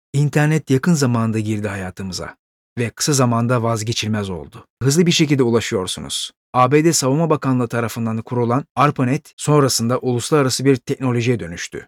Turkish Voiceover Actor Male Voice Over Talent
E-Learning Turkish Voiceover
1201e_learning.mp3